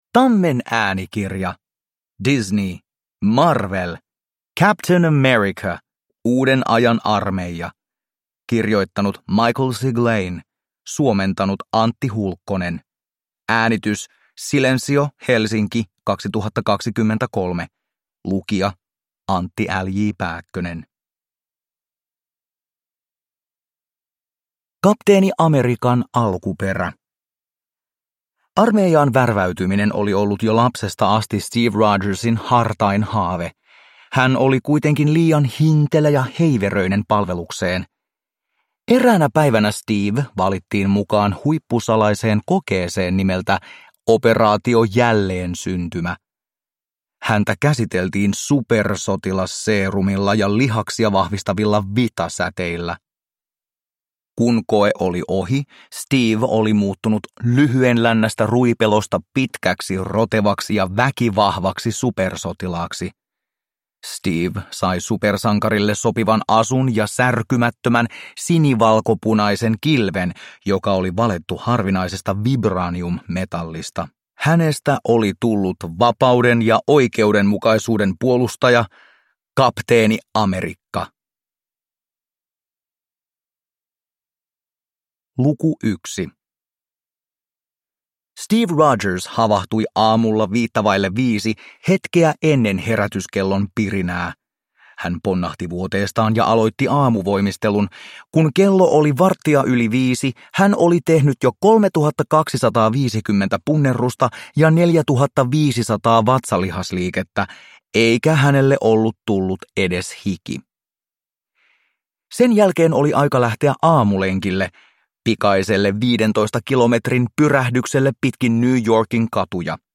Marvel. Kapteeni Amerikka. Uuden ajan armeija – Ljudbok – Laddas ner